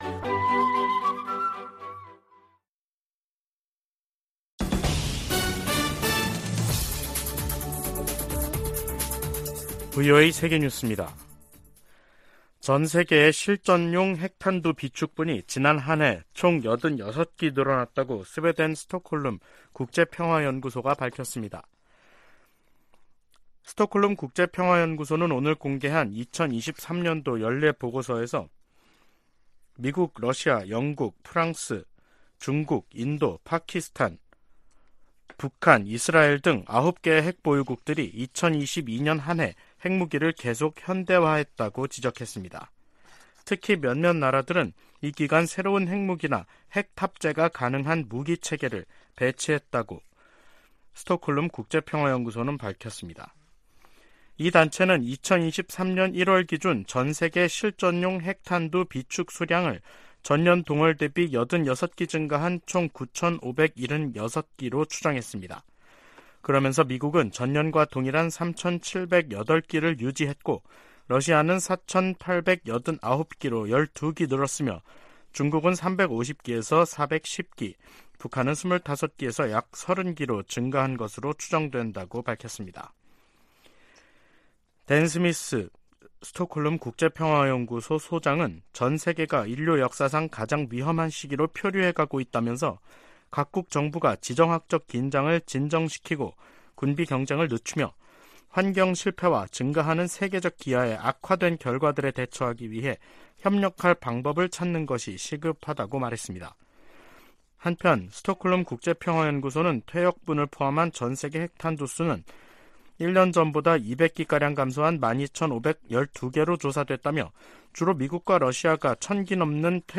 VOA 한국어 간판 뉴스 프로그램 '뉴스 투데이', 2023년 6월 12일 2부 방송입니다. 북한 열병식 훈련장에 다시 차량과 병력의 집결 장면이 관측됐습니다. 7월의 열병식 개최가 가능성이 주목되고 있습니다.